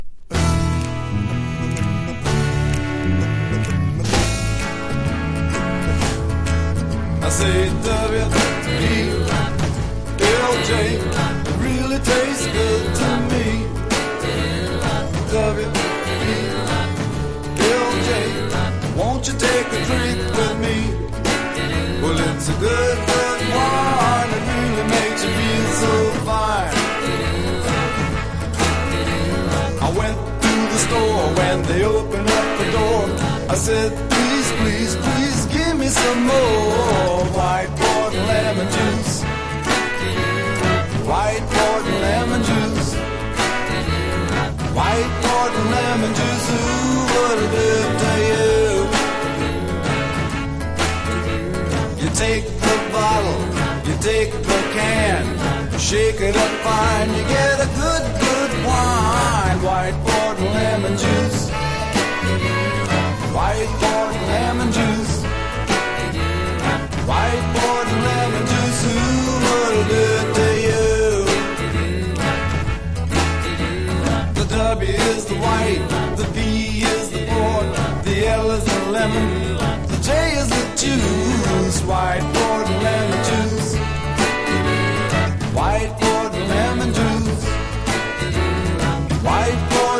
1. 70'S ROCK >
PSYCHEDELIC / JAZZ / PROGRESSIVE